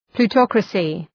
Προφορά
{plu:’tɒkrəsı} (Ουσιαστικό) ● πλουτοκρατία